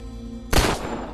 Japan Gun Sound - Bouton d'effet sonore